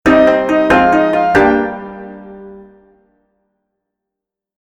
GameStartSound.mp3